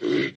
animalia_reindeer.1.ogg